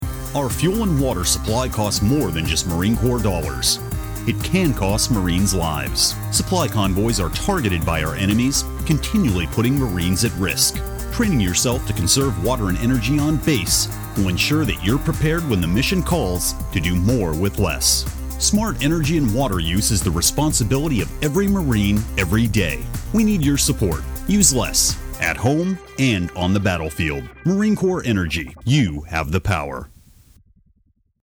Marine Corps Energy PSA - AFN Radio
Marine Corps Energy PSA.mp3